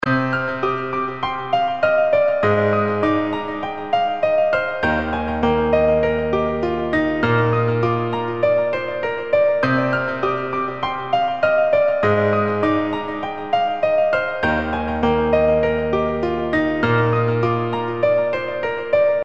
Tag: 100 bpm RnB Loops Piano Loops 3.23 MB wav Key : Unknown